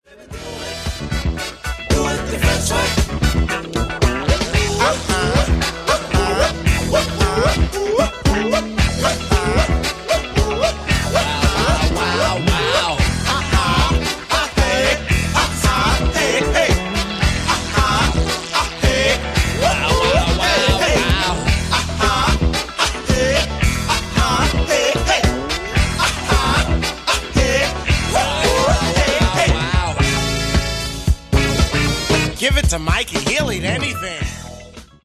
Genere:   Disco | Soul | Funk
12''Mix Extended